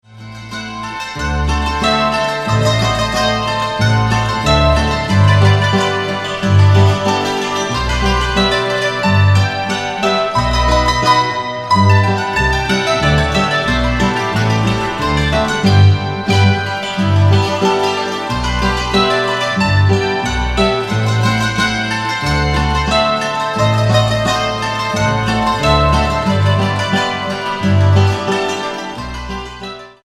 guitarra
contrabajo